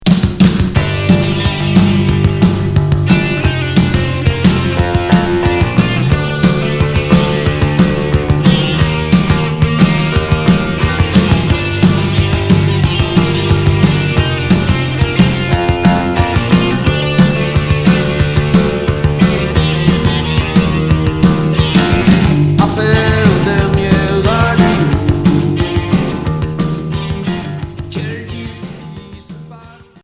Rock Unique Maxi 45t retour à l'accueil